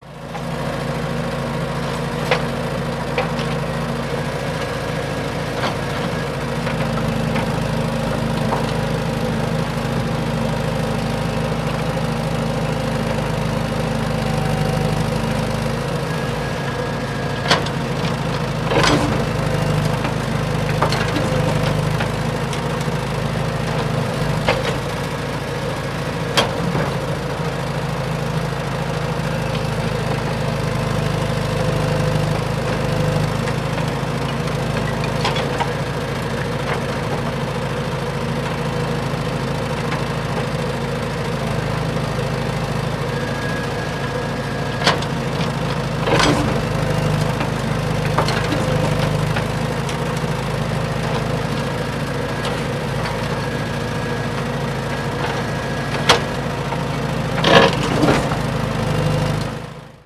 Tiếng xe Máy Múc, Máy Đào Xúc bánh xích… trong xây dựng
Thể loại: Tiếng xe cộ
Âm thanh phát ra từ các thiết bị này không chỉ vang dội mạnh mẽ mà còn rất đặc trưng, thường được sử dụng trong các dự án chỉnh sửa video và tạo hiệu ứng âm thanh
tieng-xe-may-muc-may-dao-xuc-banh-xich-trong-xay-dung-www_tiengdong_com.mp3